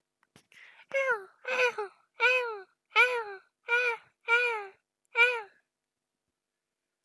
seagulls.wav